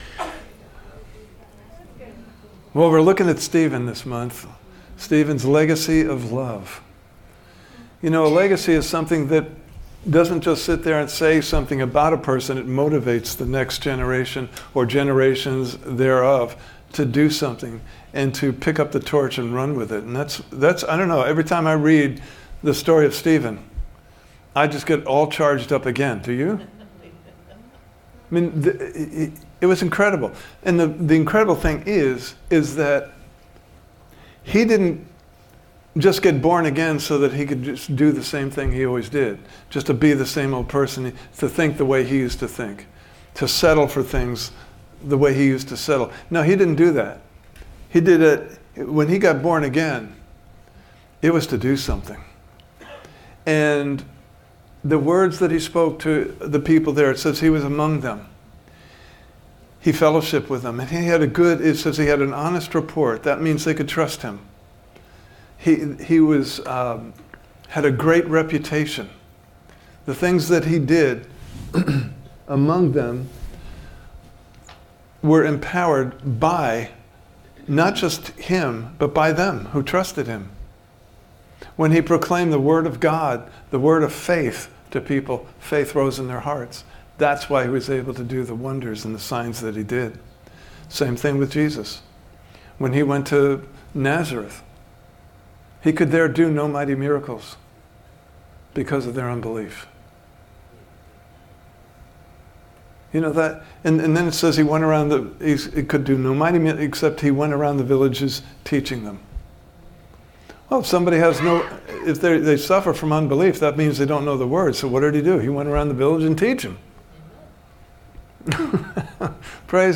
Series: Stephen’s Legacy of Love Service Type: Sunday Morning Service « You Are Jesus’ Valentine!